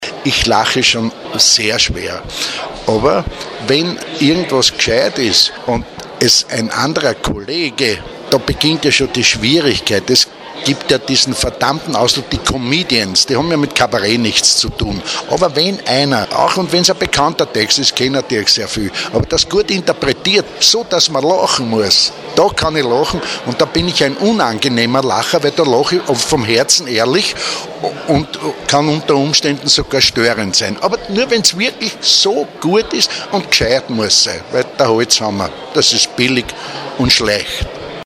Statement